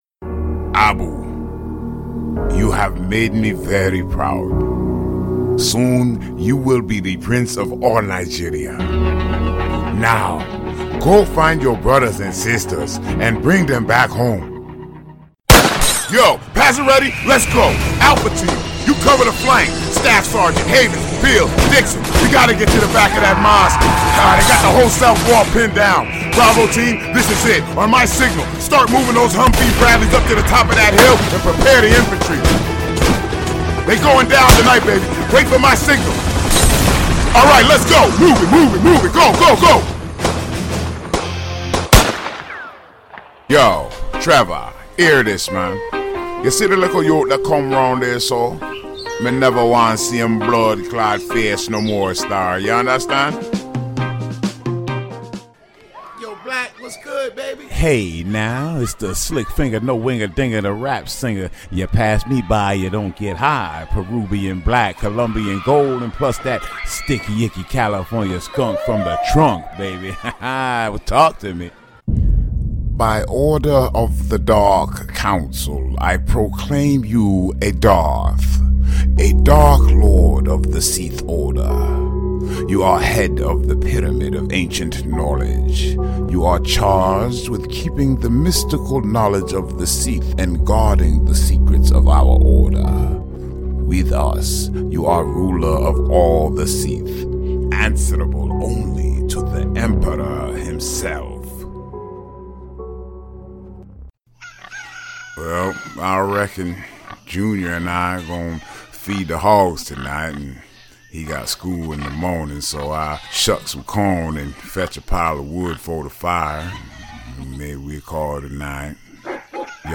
voiceover : animation